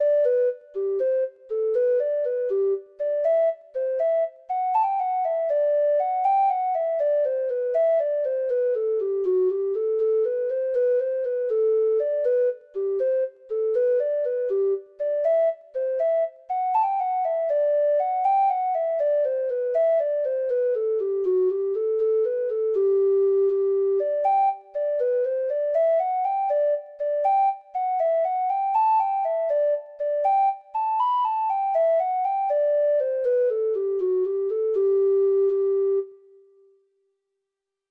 Free Sheet music for Treble Clef Instrument
Irish